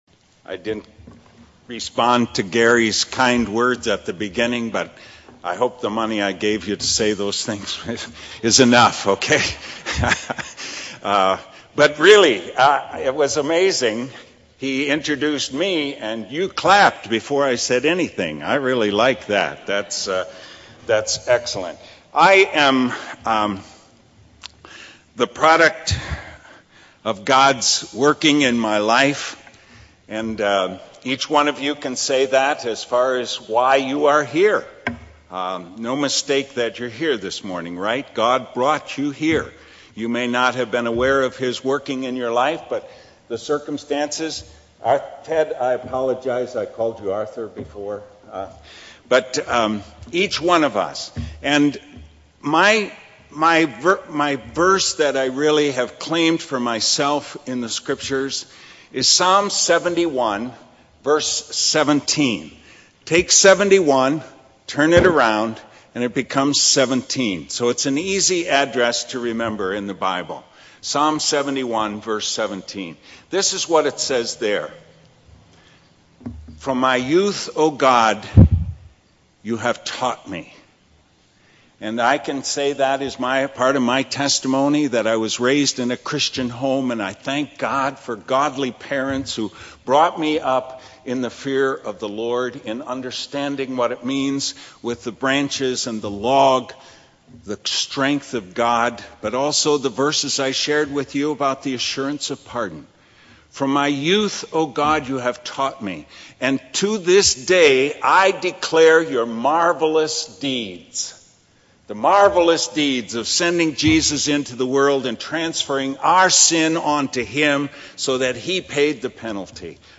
Sermons and Anthems | The Second Reformed Church of Hackensack